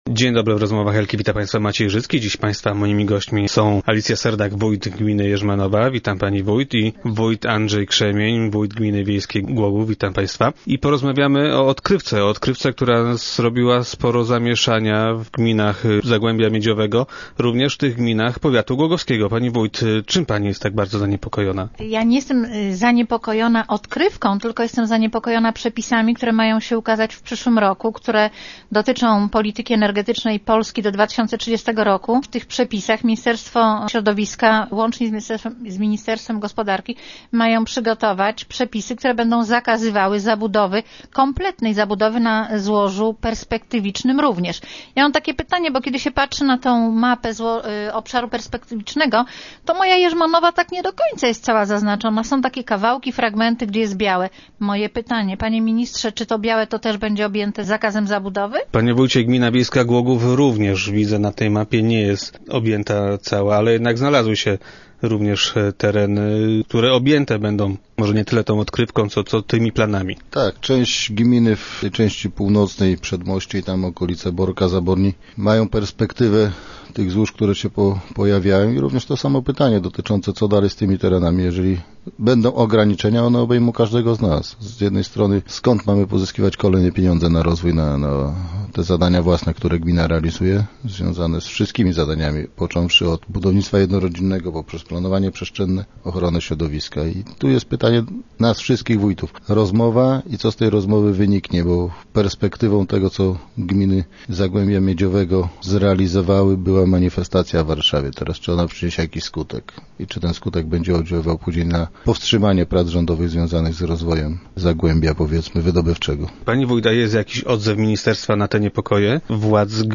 - Może to zahamować nasz rozwój - twierdzą Alicja Serdak, wójt Jerzmanowej i Andrzej Krzemień, wójt gminy wiejskiej Głogów.